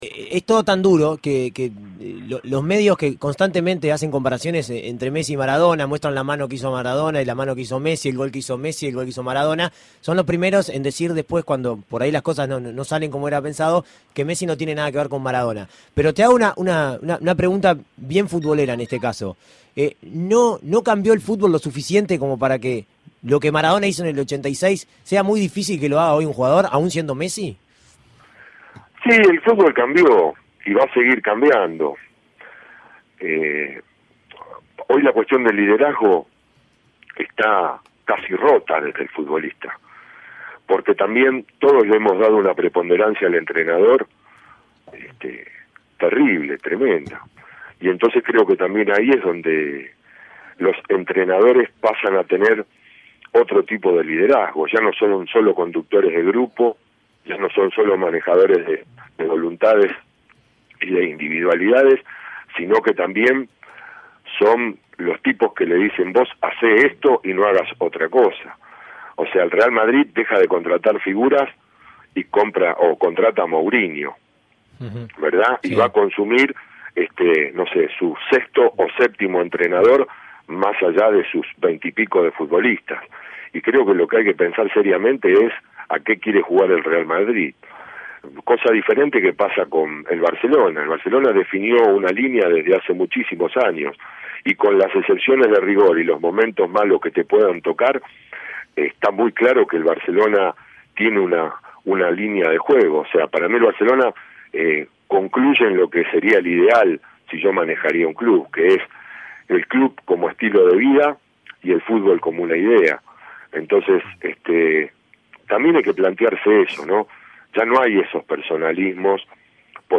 Segunda parte de la entrevista